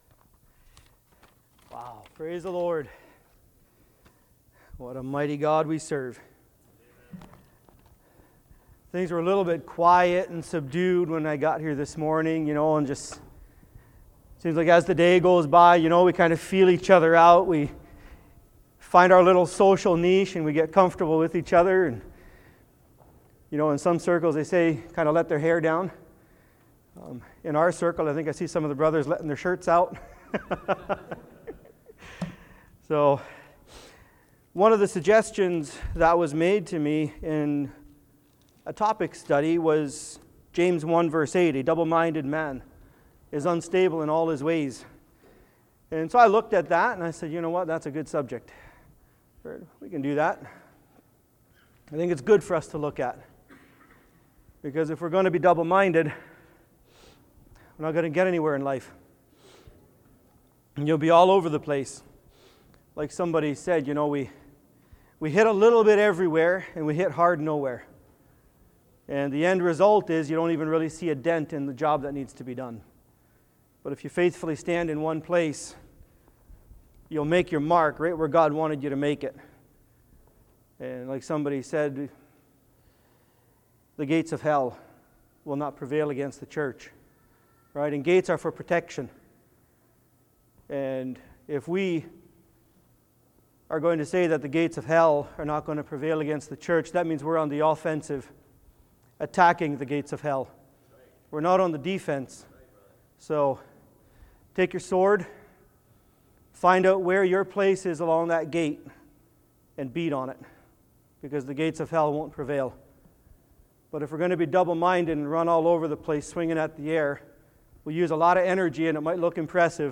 Series: Fellowship Weekend Service Type: Saturday Afternoon